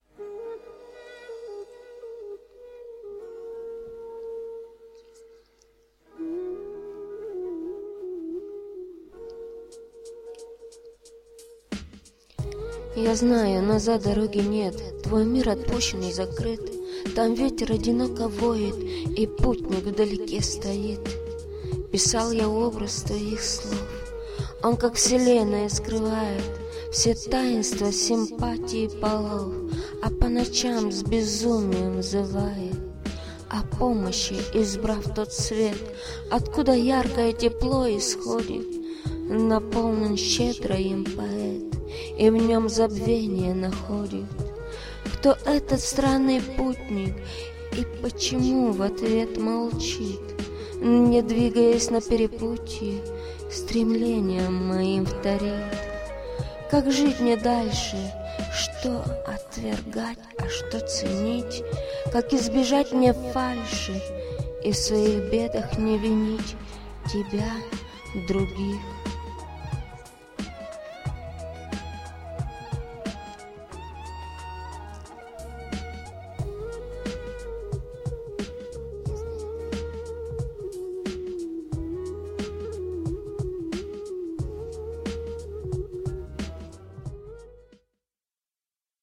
ТИП: Музика
СТИЛЬОВІ ЖАНРИ: Ліричний
ВИД ТВОРУ: Пісня